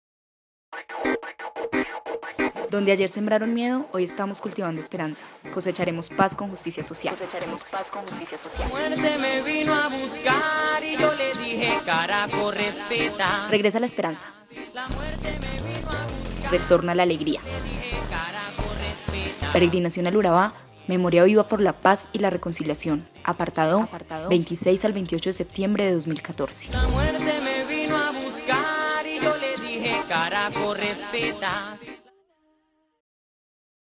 Cuña Urabá (MP3 – 1 MB)